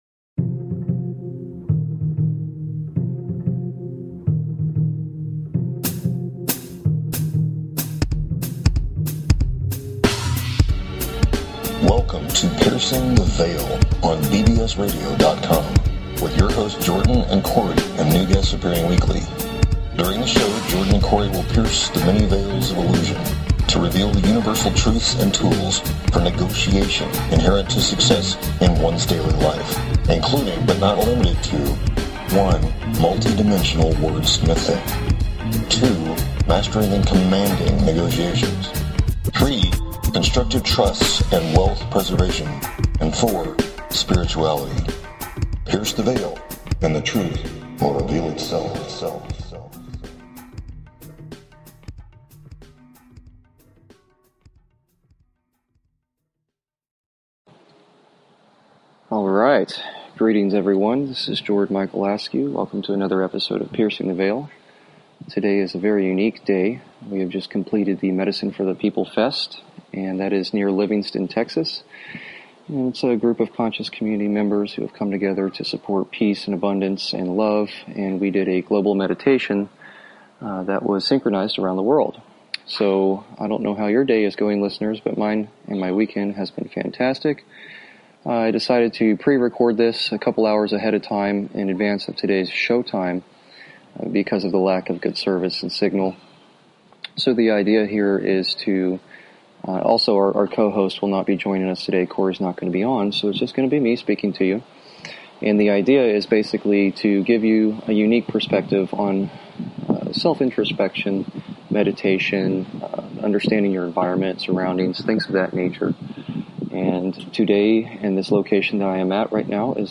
Pre-recorded on Indian Lands During the Worlds Largest Coordinated Meditation for World Peace and Prosperity. Short Impromptu Guided Meditation, Discussion about consciousness, Self Observation, and the Many Forms of Meditation.